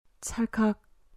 찰칵 チャルカ カシャ